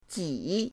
ji3.mp3